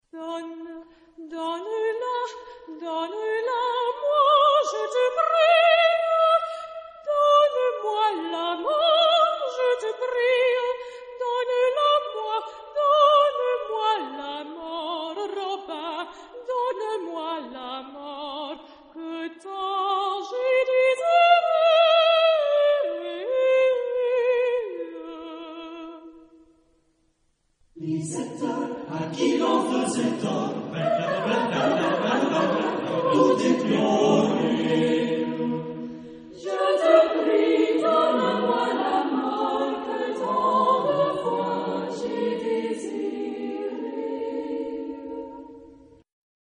Genre-Style-Form: Secular ; Contemporary ; Partsong
Mood of the piece: descriptive ; change of beat
Type of Choir: SATB  (4 mixed voices )
Soloist(s): Soprano (1) ad libitum  (1 soloist(s))
Tonality: E tonal center